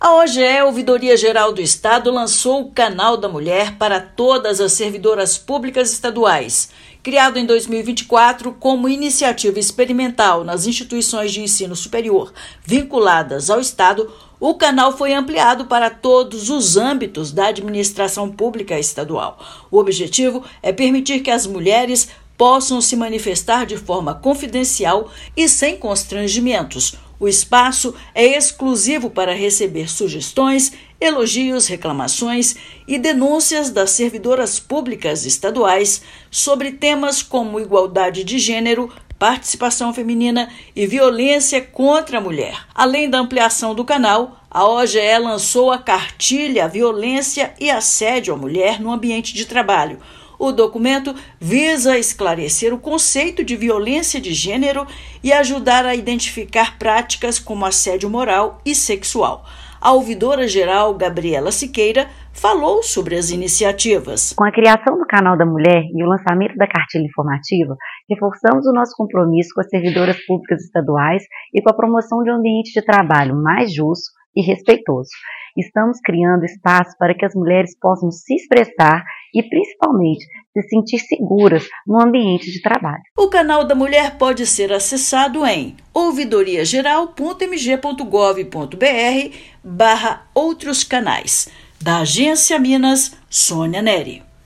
Ouvidoria-Geral do Estado expande atendimento pelo Canal da Mulher e lança cartilha, com orientações para que todos sejam agentes de transformação e promovam ambiente de trabalho sempre livre de violência e discriminação. Ouça matéria de rádio.